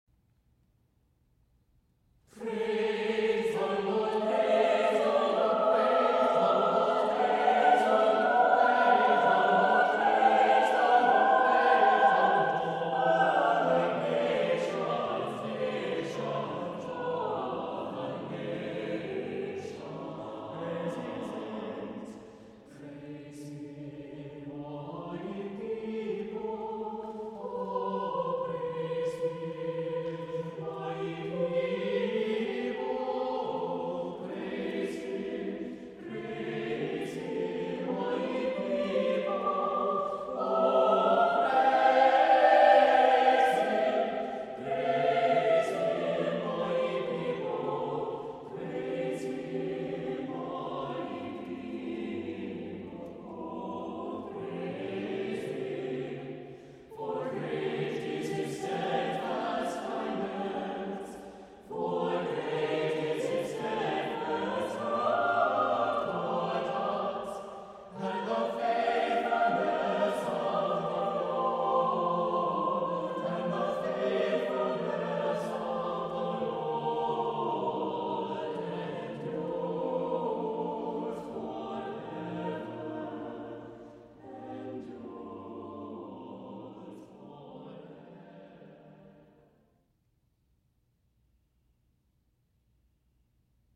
• A bright ,energetic setting of Psalm 117,
• scored for a cappella SATB